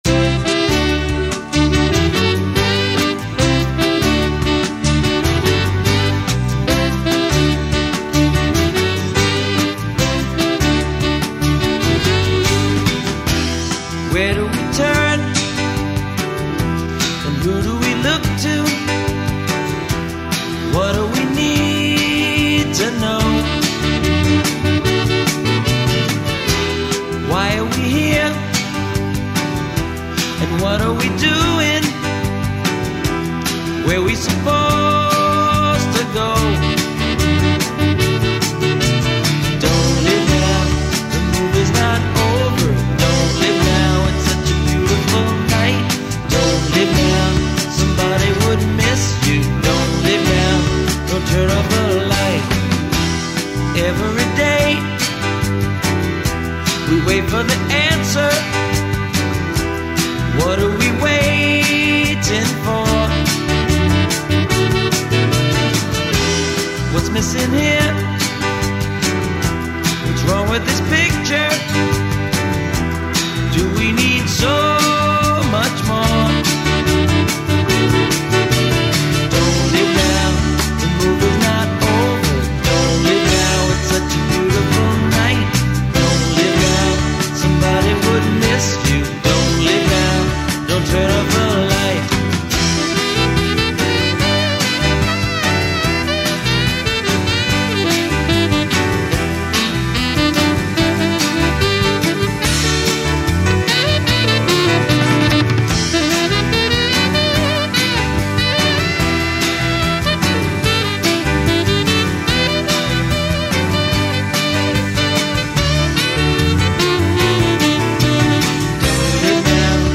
guitar, bass, vocals
drums
keyboards
saxophone, flute